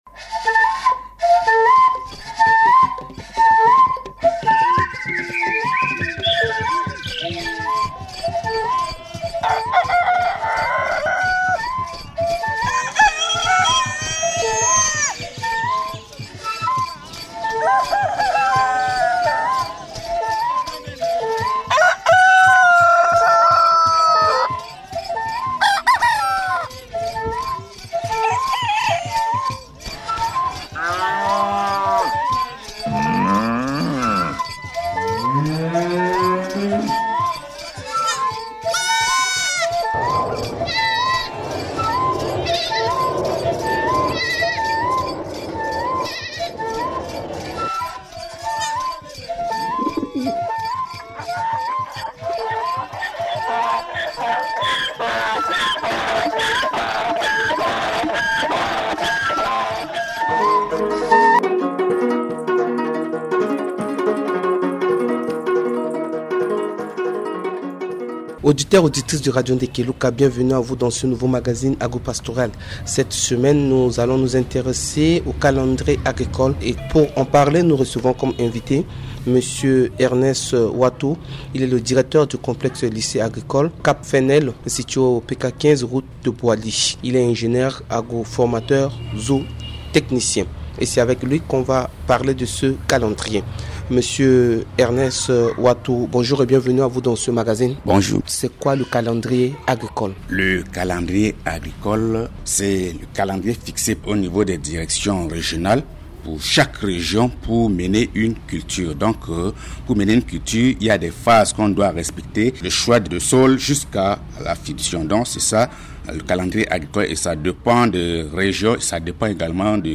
Dans le magazine agropastoral de ce jeudi 13 décembre, soyez nombreux à l’écoute à partir de 9h 05mn pour en savoir davantage sur le calendrier agricole.